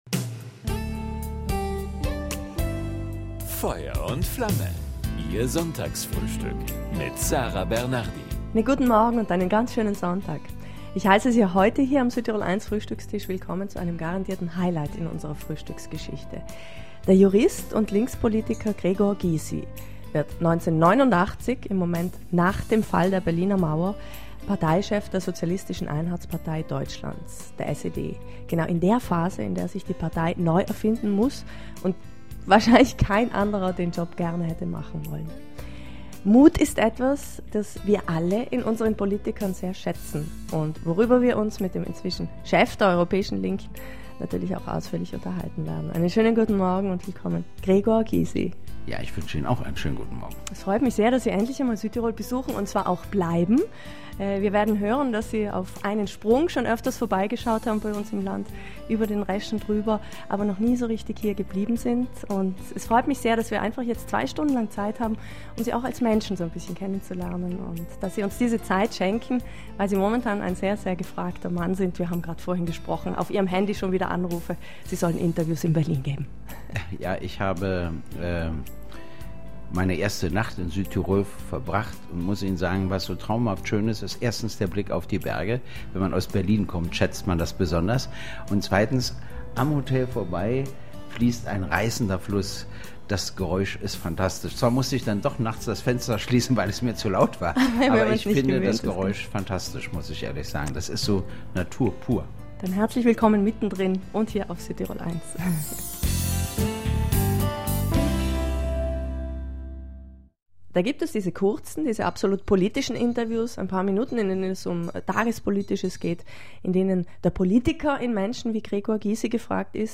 Mit seiner kürzlich erschienenen Autobiographie „Ein Leben ist zu wenig“ hat Gregor Gysi viele Leser bis nach Südtirol begeistert. Von seinen Aufs und Abs, politisch wie privat, hat der beliebte, aber nicht ganz unumstrittene Polit-Popstar diesmal in „Feuer und Flamme“ zum Frühstück erzählt…